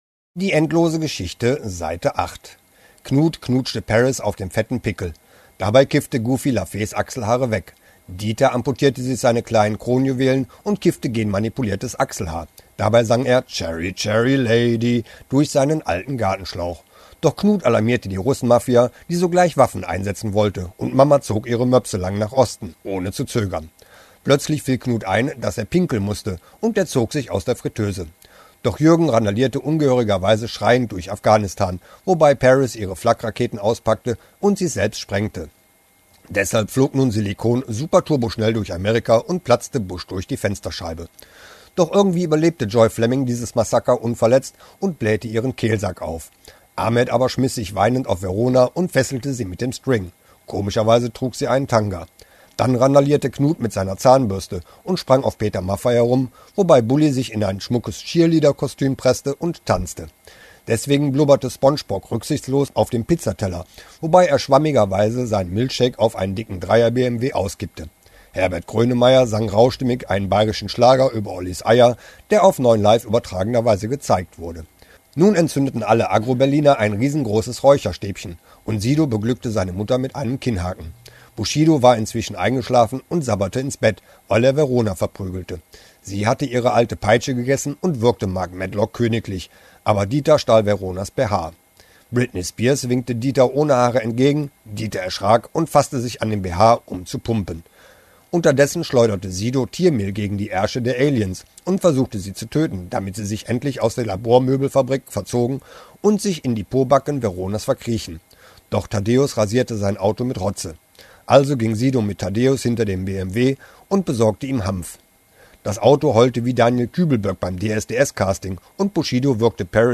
Das Hörbuch zur endlosen Story, Seite 8